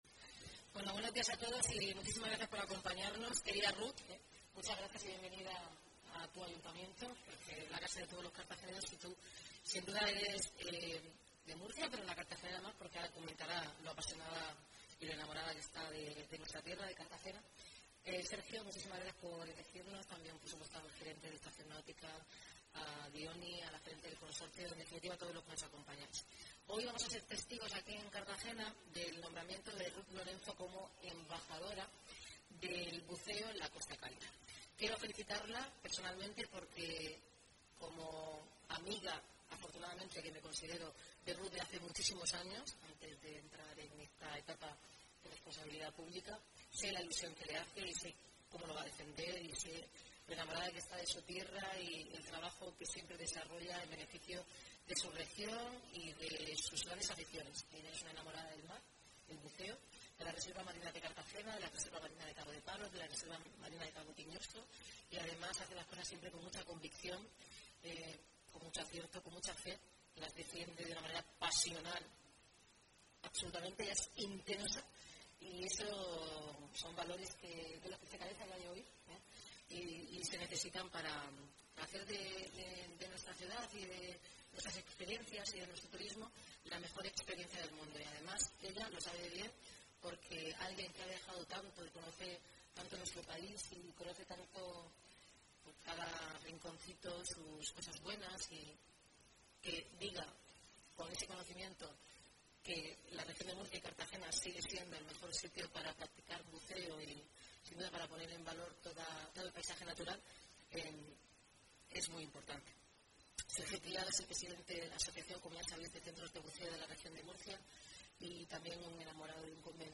El Palacio Consistorial de Cartagena ha acogido este 9 de diciembre el nombramiento de la artista murciana Ruth Lorenzo como embajadora de los centros de buceo de la Región.